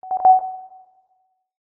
borealis_notify.mp3